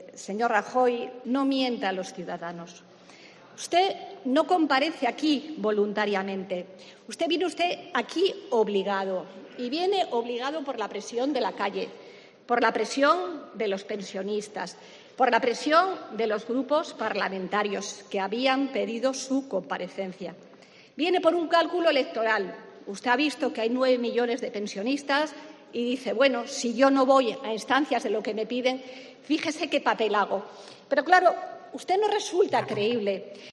Margarita Robles en el Congreso
"¡Debería darle vergüenza que con un incremento de dos euros al mes usted venga aquí a alardear y hacer el discurso que ha hecho!", ha cargado Robles contra Rajoy en una intervención vehemente y sin papeles, en la que la portavoz socialista ha acusado al presidente de "mentir", de no resultar "creíble" y de estar alejado de la "realidad que se está viviendo en la calle".
Animada por los constantes aplausos de los suyos, Robles ha reprochado a Rajoy que su modelo de crecimiento económico sea "el que recae en los hombros de los ciudadanos" y esté "basado en la desigualdad".